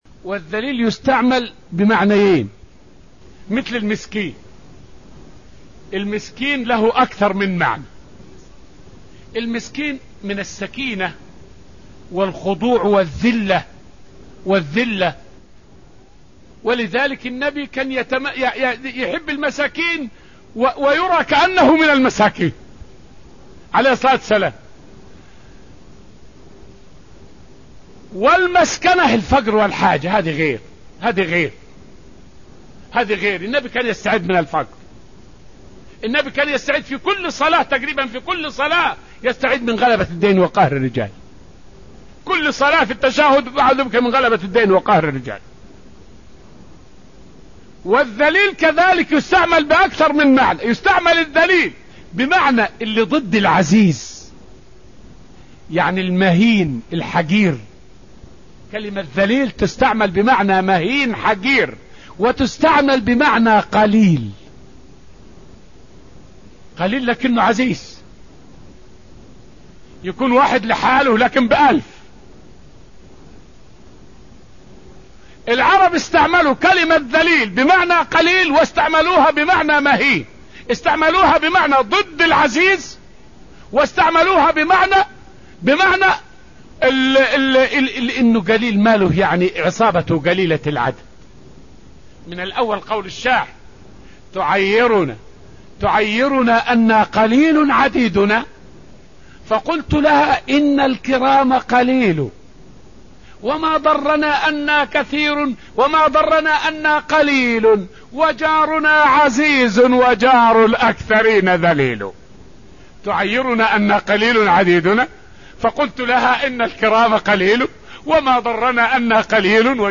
فائدة من الدرس الثاني من دروس تفسير سورة آل عمران والتي ألقيت في المسجد النبوي الشريف حول معنى قوله تعالى {وأنتم أذلة}.